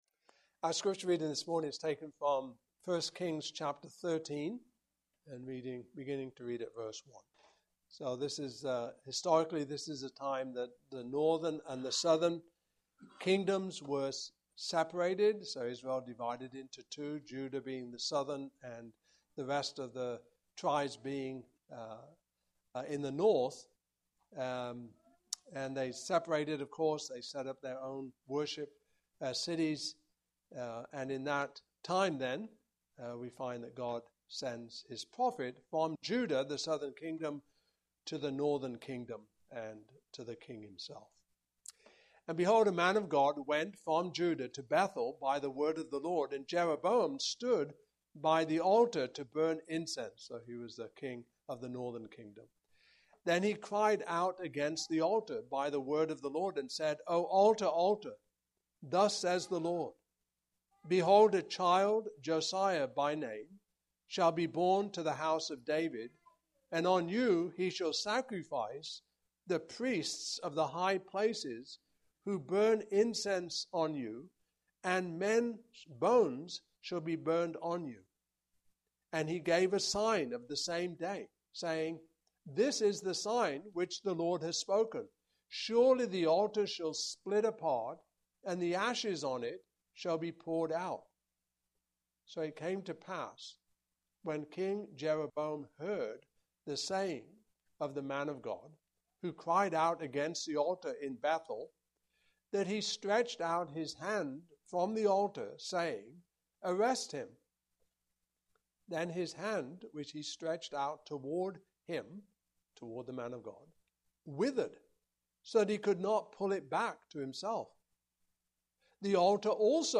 Passage: I Kings 13:1-34 Service Type: Morning Service